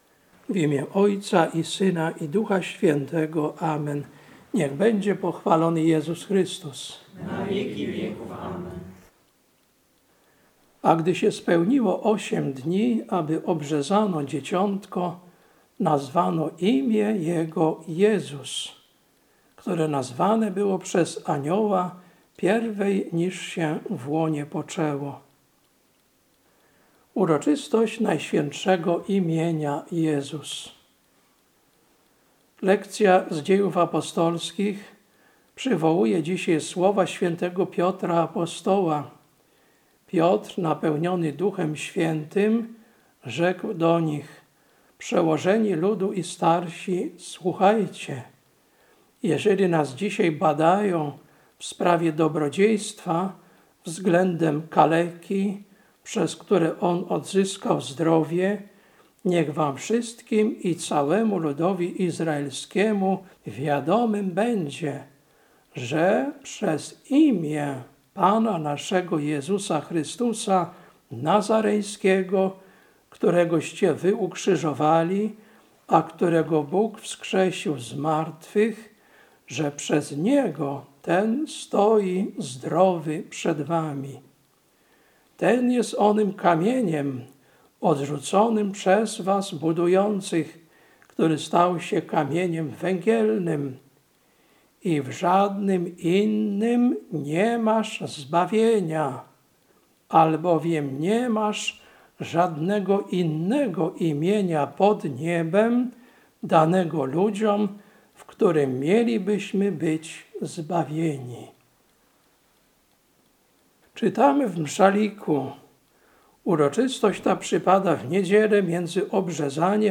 Kazanie na uroczystość Najświętszego Imienia Jezus, 5.01.2025 Lekcja: Dz 4, 8-12 Ewangelia: Łk 2, 21